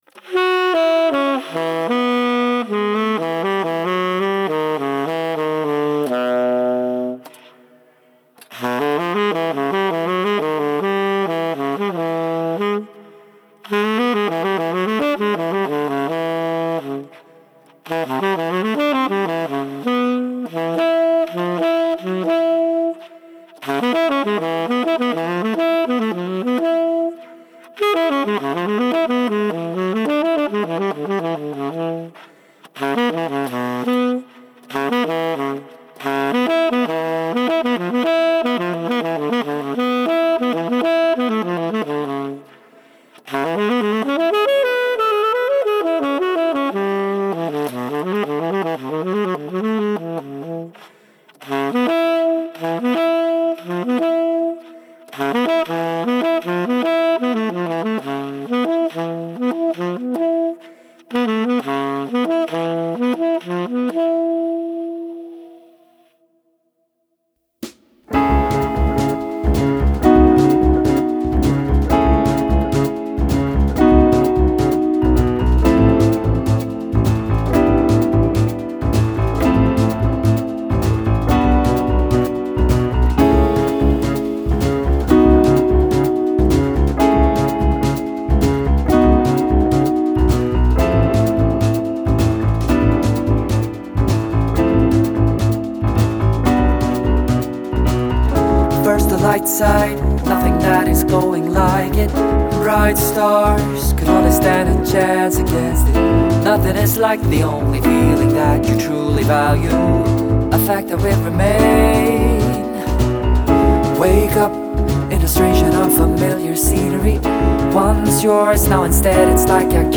saxofonista
jazz contemporáneo muy sutil y a la vez dinámico